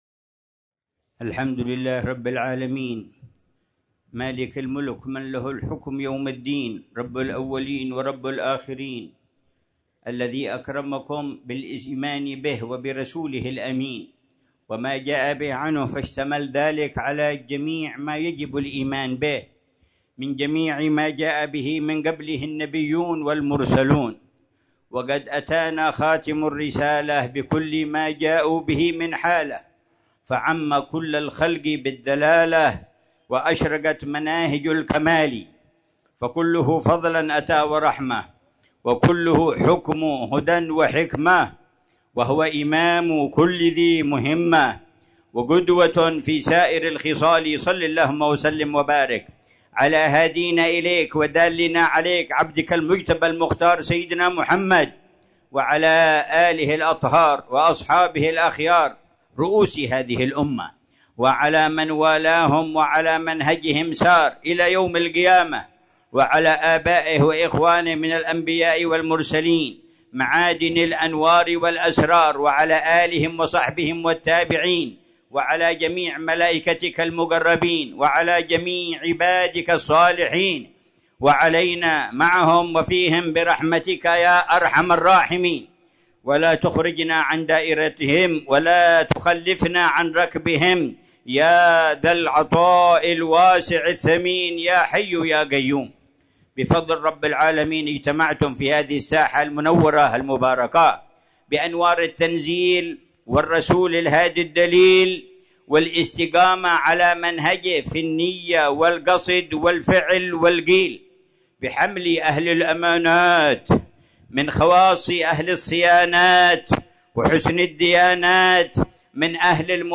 محاضرة
في ساحة مسجد طه بمدينة سيئون